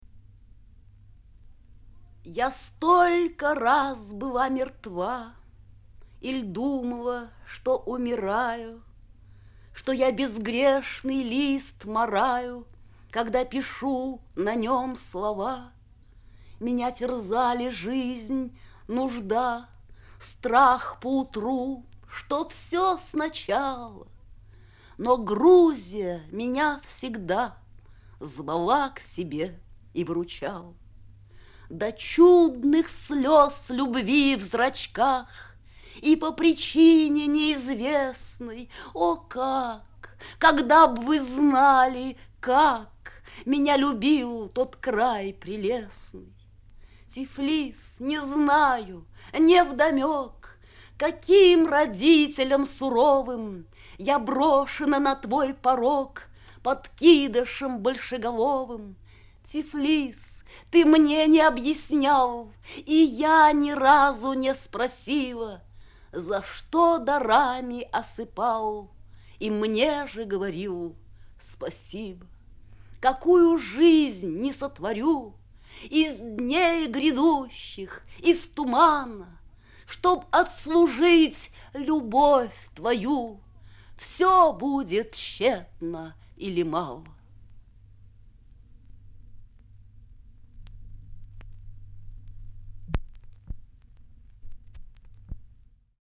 3. «Белла Ахмадулина – Я столько раз была мертва… (читает автор)» /